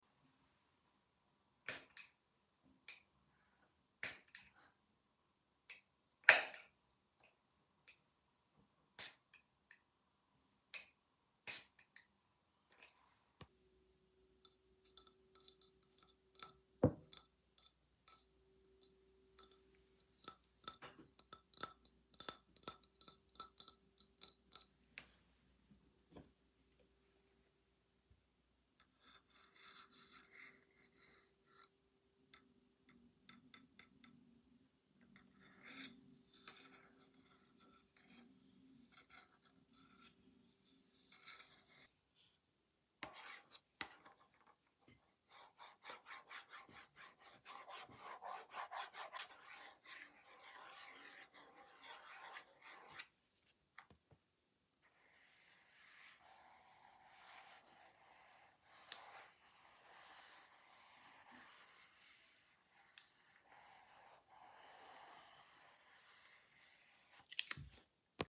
Midnight sounds, mystery triggers.